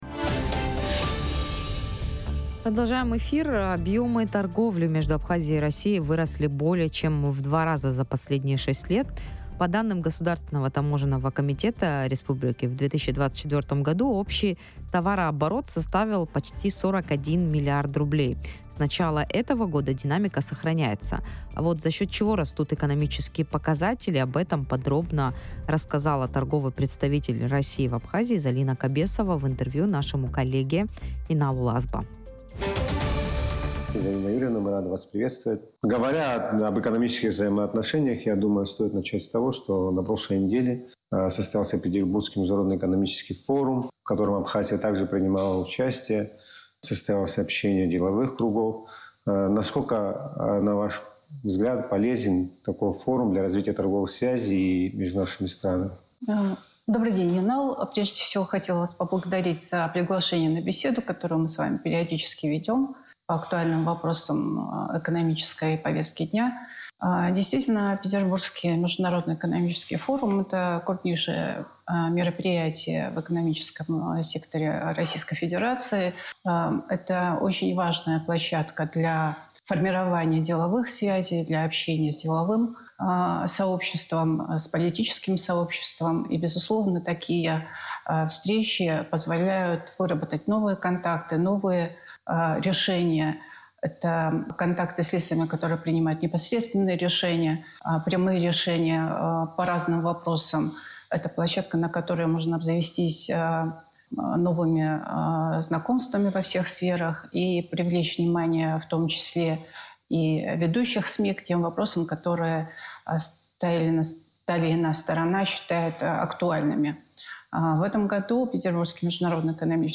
Развитие российско-абхазских торговых связей. Интервью с торгпредом
Россия сохраняет ключевую позицию во внешнеэкономических связях Абхазии, рассказала в пресс-центре Sputnik торгпред РФ в республике Залина Кобесова. Доля России во внешнеторговом обороте Абхазии составляет 72% и достигает 41,2 млрд рублей...